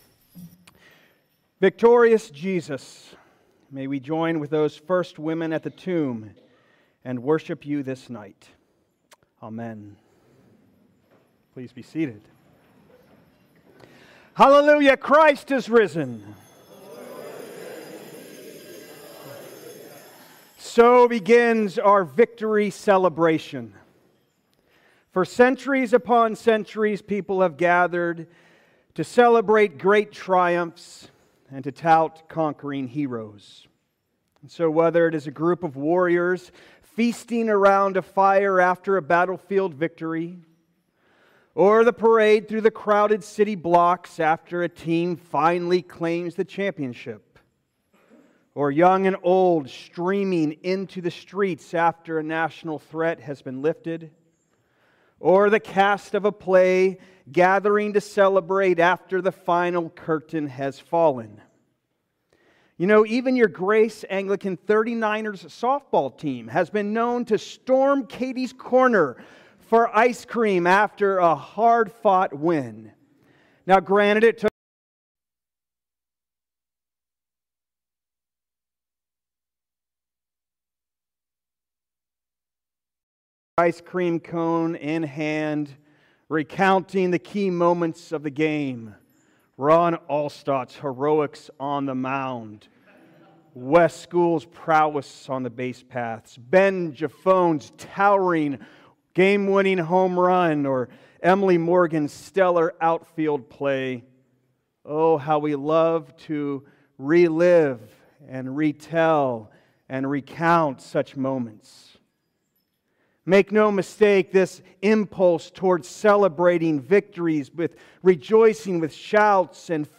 2026 Sermons The Victory Train - A Vigil Reflection Play Episode Pause Episode Mute/Unmute Episode Rewind 10 Seconds 1x Fast Forward 30 seconds 00:00 / 00:18:46 Subscribe Share RSS Feed Share Link Embed